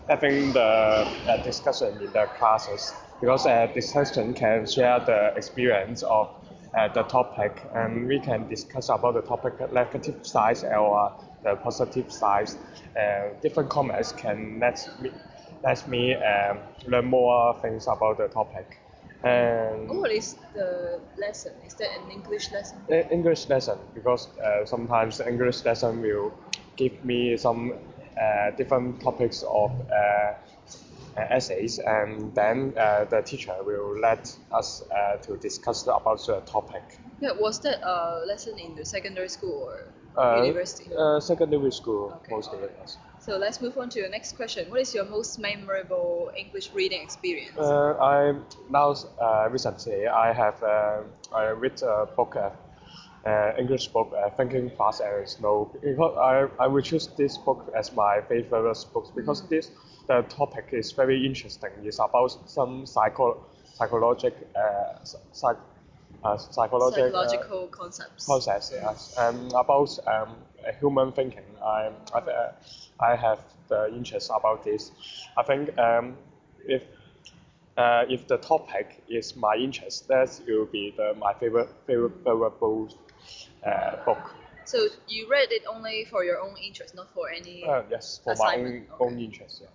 Subcategory: Non-fiction, Reading, Speech